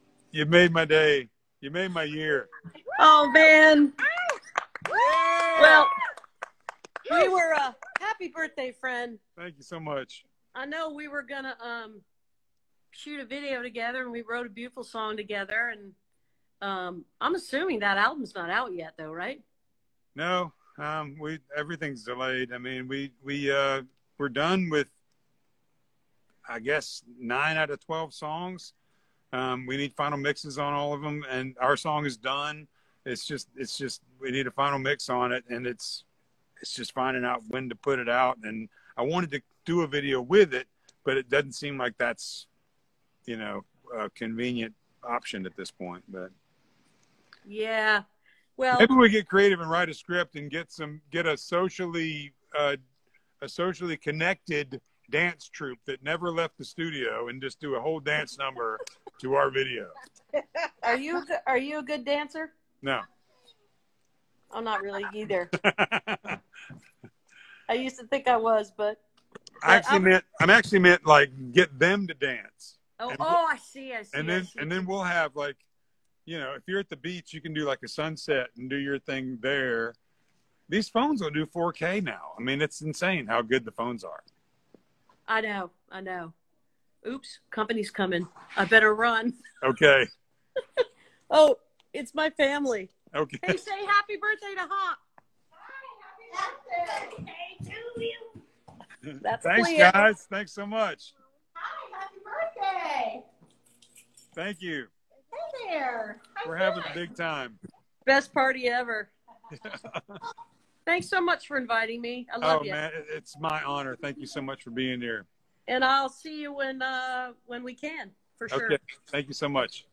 (captured from an instagram live video stream)
03. talking (john driskrell hopkins and emily) (2:48)